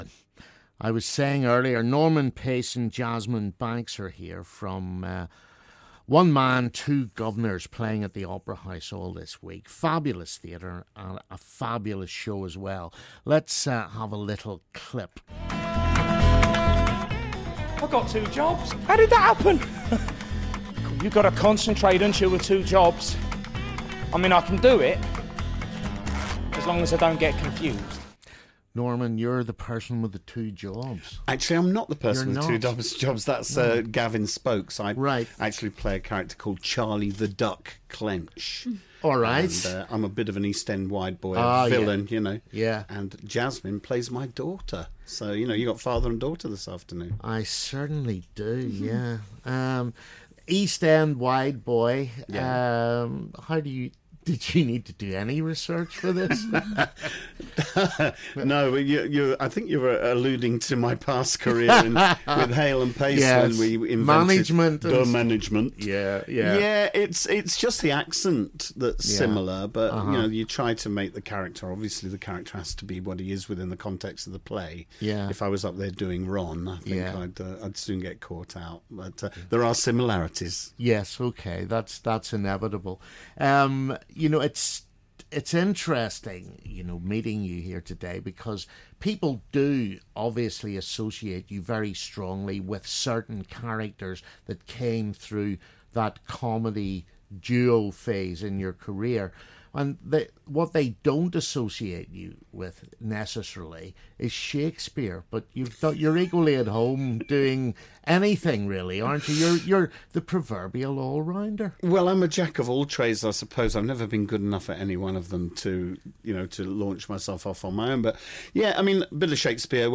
One Man, Two Guvnors live in the studio